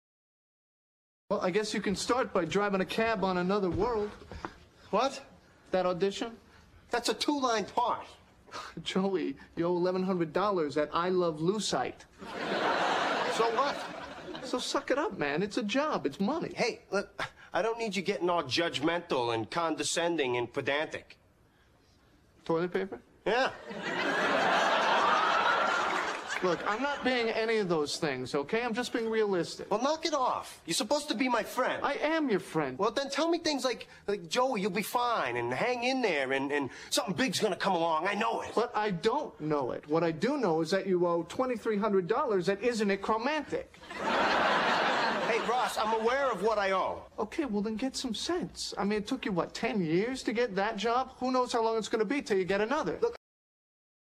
在线英语听力室影视剧中的职场美语 第55期:前途渺茫的听力文件下载,《影视中的职场美语》收录了工作沟通，办公室生活，商务贸易等方面的情景对话。